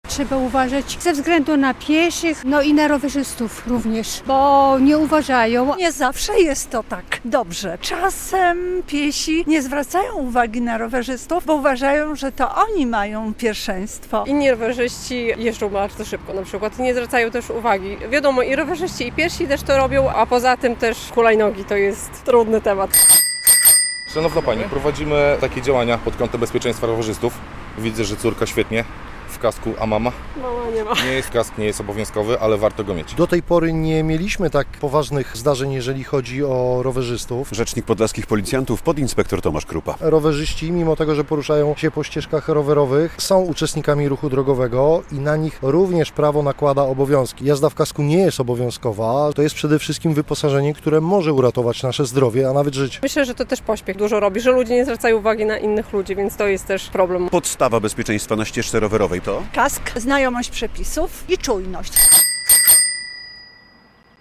relacja
Białostoccy użytkownicy jednośladów z którymi rozmawialiśmy mówią, że na ścieżkach rowerowych nie zawsze jest bezpiecznie.